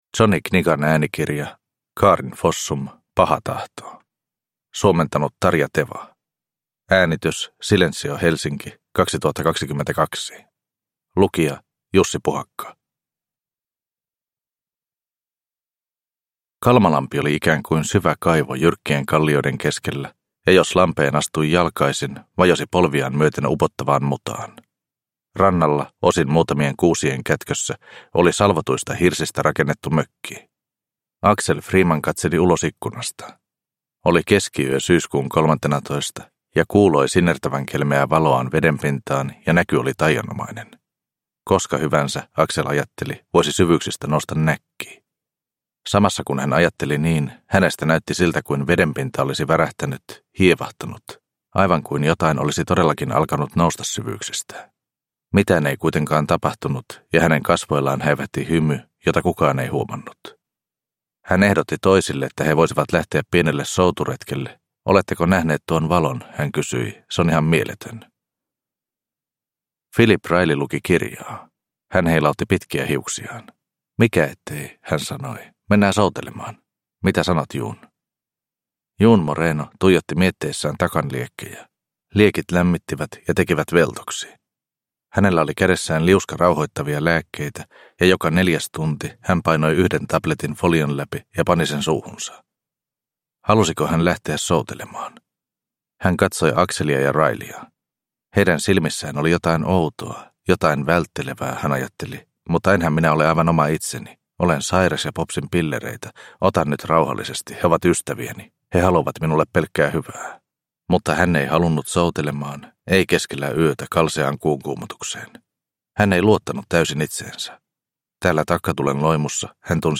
Paha tahto – Ljudbok – Laddas ner